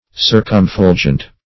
Search Result for " circumfulgent" : The Collaborative International Dictionary of English v.0.48: Circumfulgent \Cir`cum*ful"gent\, a. [Pref. circum- + fulgent.] Shining around or about.